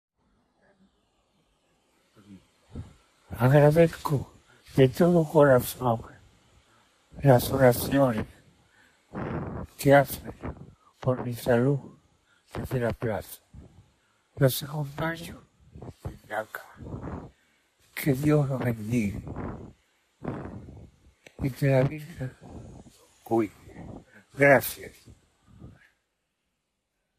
Intorno alle 21 di giovedì 6 marzo, prima di dare il via all’undicesima recita del Rosario, un audio di meno di 30 secondi in spagnolo registrato da Francesco al Policlinico Gemelli viene trasmesso in filodiffusione per le centinaia di fedeli riuniti nella piazza (